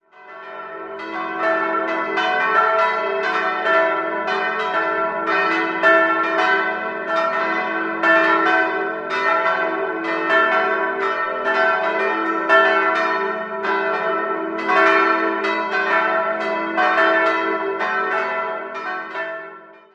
Die großen Figuren für den neuen Kirchenraum schuf Josef Henselmann. 4-stimmiges Gloria-TeDeum-Geläute: e'-fis'-a'-h' Die Glocken 1 bis 3 wurden 1950 von Johann Hahn in Landshut gegossen, Glocke 4 stammt aus der Gießerei Hamm in Regensburg aus dem Jahr 1921.